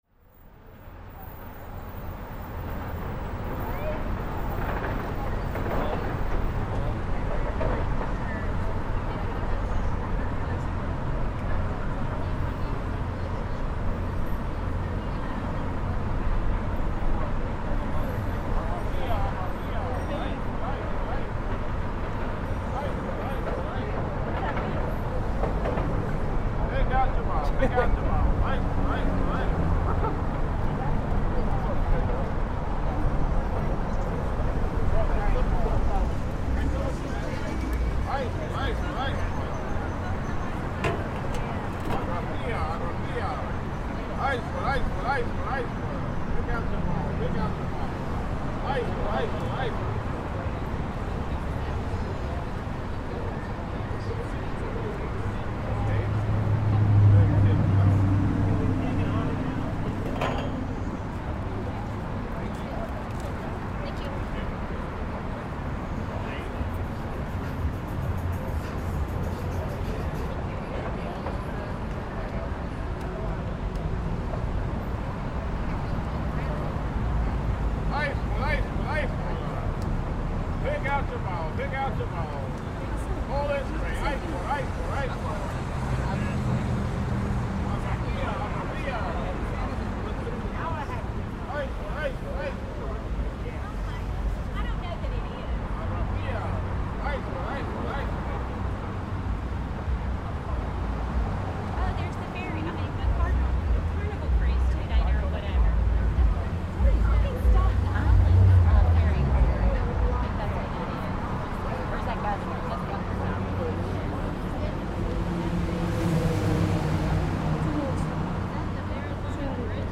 On Brooklyn Bridge
In the middle of the famous Brooklyn Bridge, we can hear the sound of hundreds of tourists both on foot and on bicycles, with the background roar of thousands of vehicles passing through. In the foreground, enterprising salesmen offer bottles of ice cold water to passers-by.